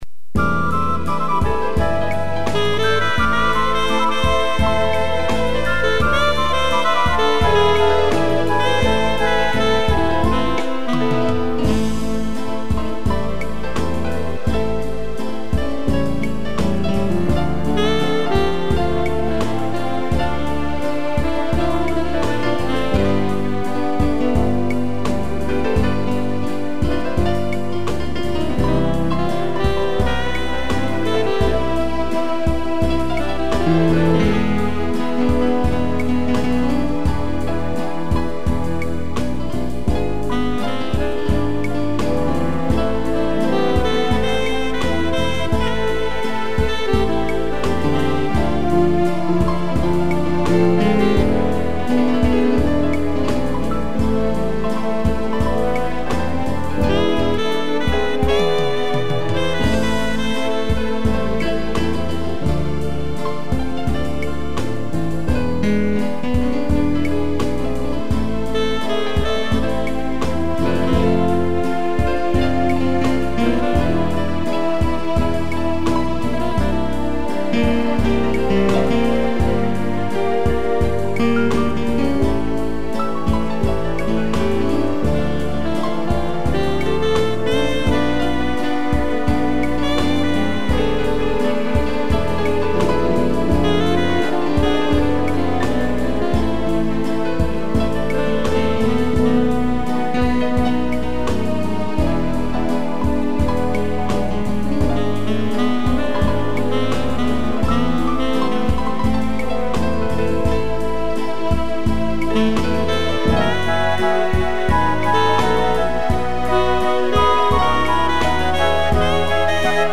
piano, tutti e sax
instrumental